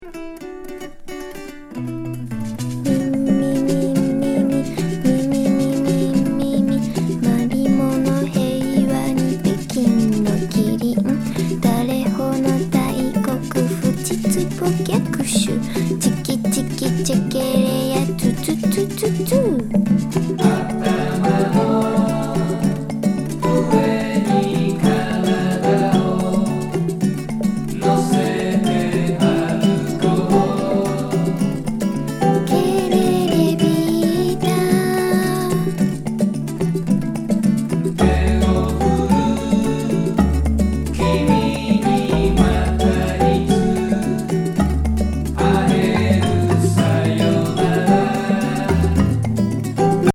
女優エキセントリック・スムース・シンセ・ポップ全4曲収録。